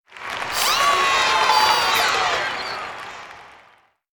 KART_Applause_2.ogg